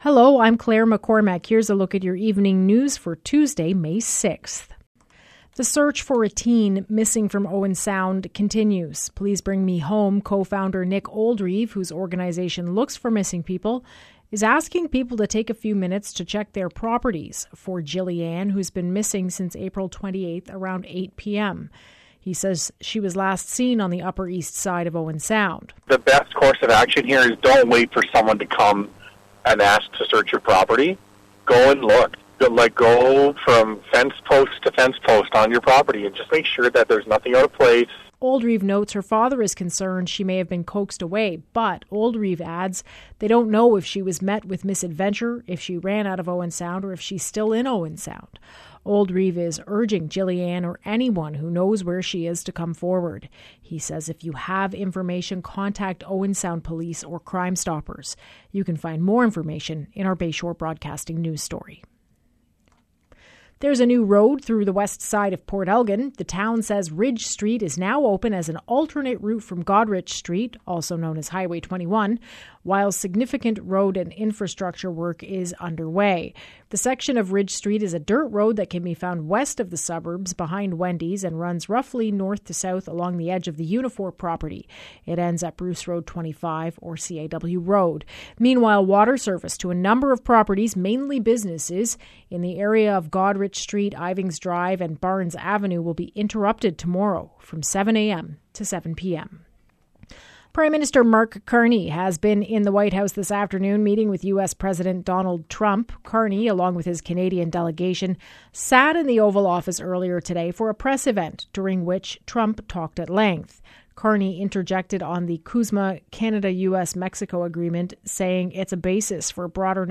Evening News – Tuesday, May 6